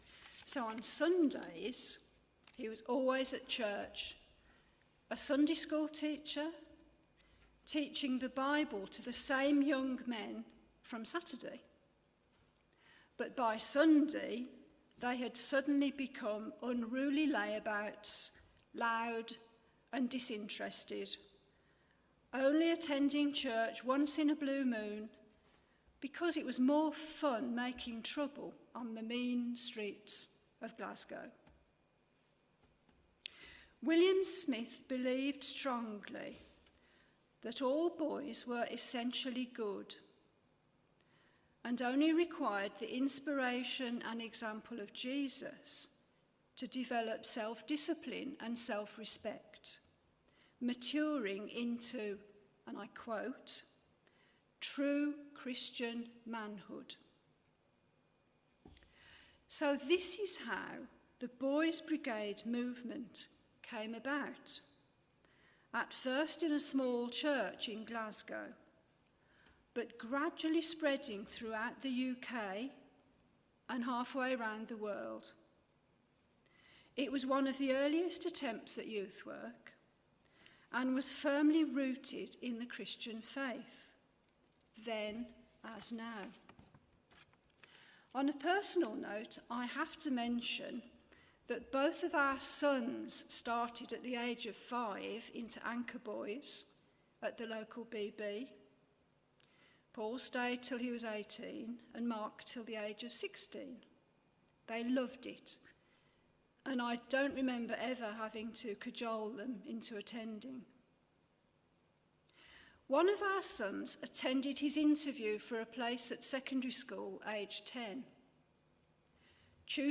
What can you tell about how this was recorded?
Media for Midweek Communion on Wed 25th Jun 2025 10:00 Speaker